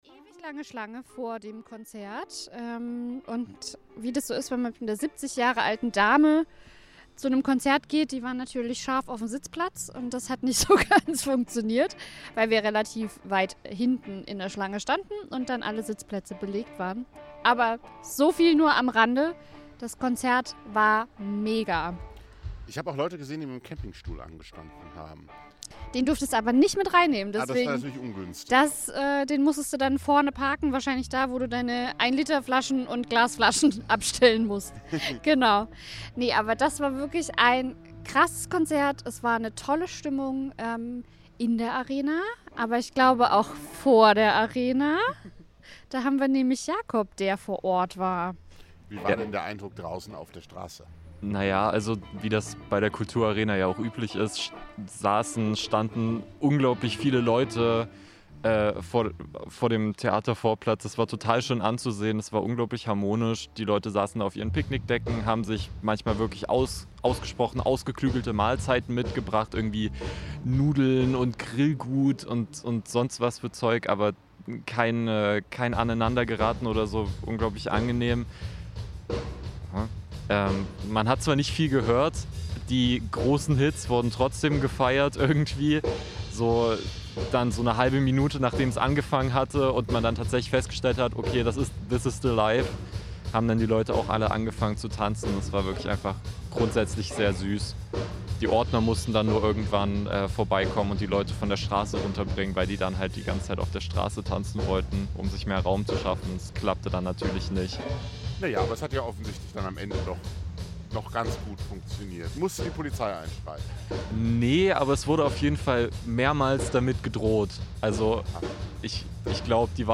Liveberichterstattung vom Theatervorplatz.